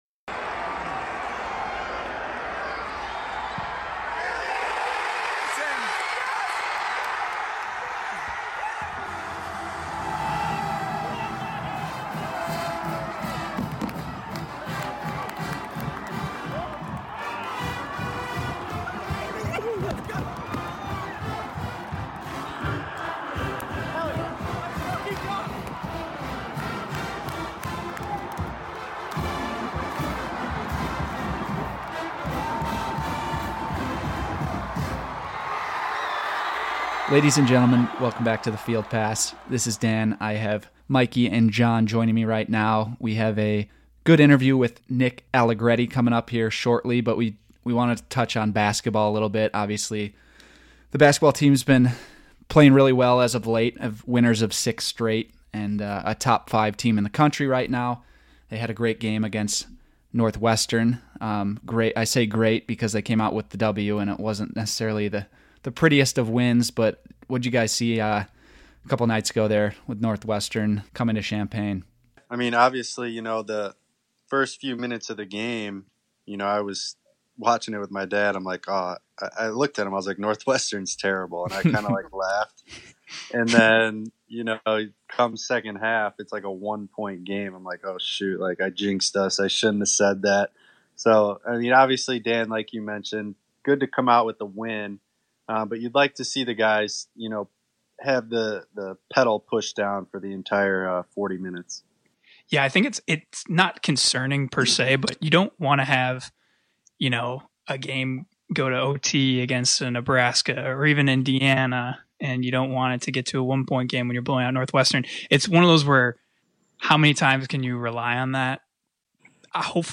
Nick Allegretti Interview